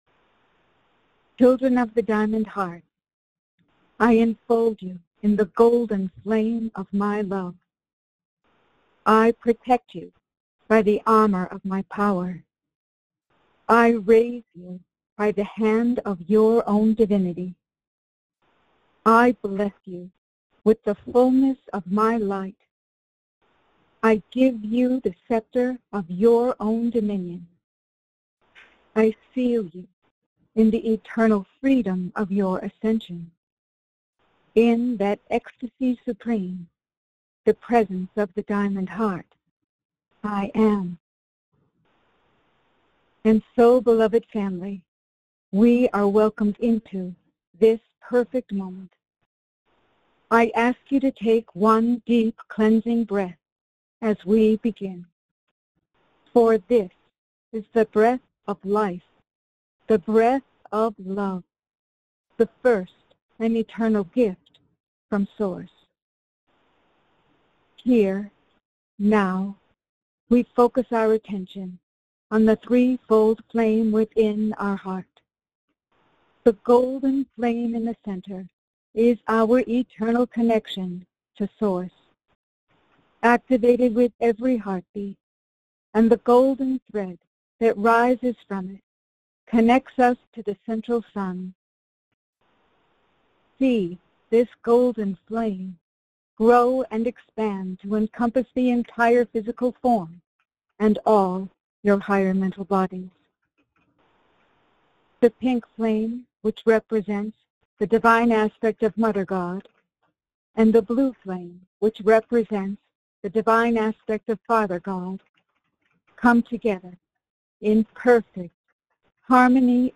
Join Lord Sananda in group meditation.